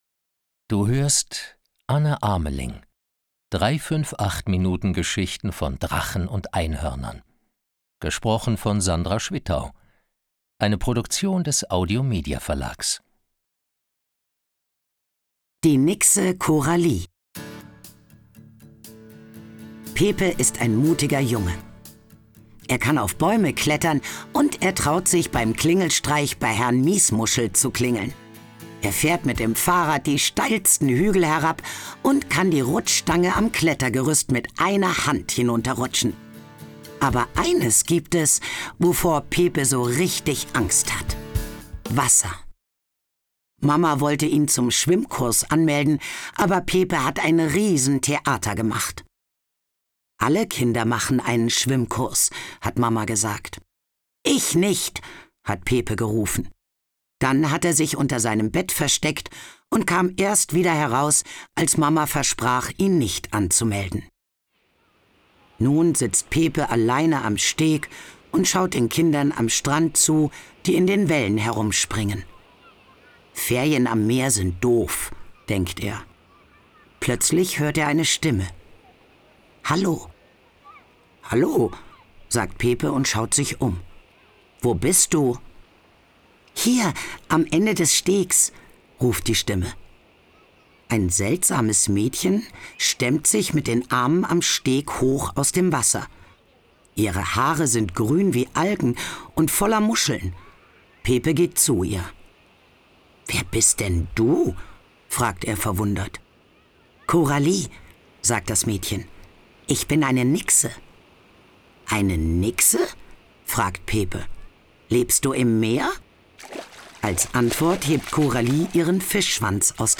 Ihre markante, freche Stimme macht jedes Hörbuch zu einem witzigen und packenden Erlebnis.
Mitarbeit Sprecher: Sandra Schwittau